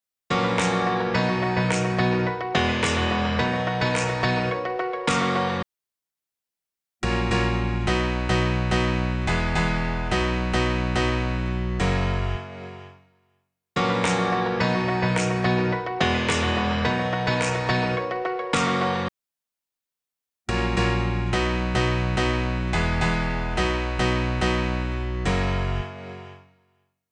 Опять про рояли
Пример: сначала то что нужно, потом мой вариант Piano.mp3 Может кто-нибудь подтолкнёт в правильном направлении?